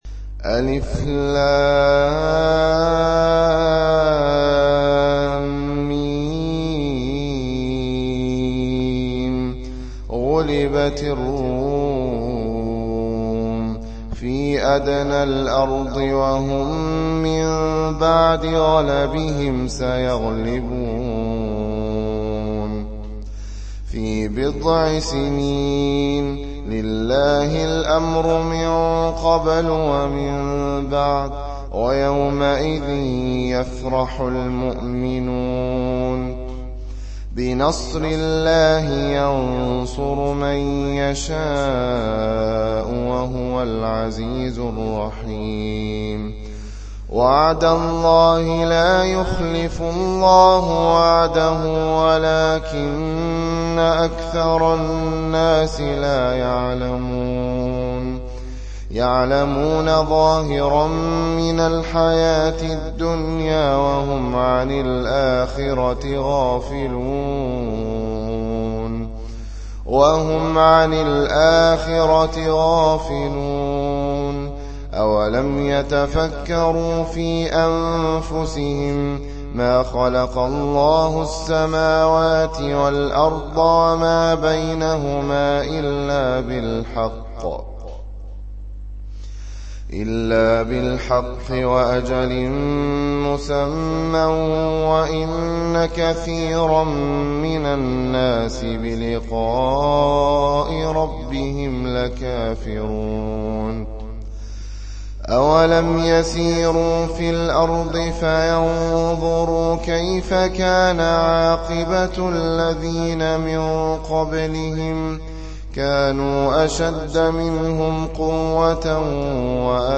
الروم القارئ: فضيلة الشيخ مجموعة مشائخ وعلماء الصنف: تلاوات تاريخ: الأربعاء 07 ذي القعدة 1440 هـ الموافق لـ : 10 جويلية 2019 م رواية : حفص عن عاصم الحجم:4.0M المدة :00:17:25 حمله :78 سمعه :119 سماع التلاوة تحميل التلاوة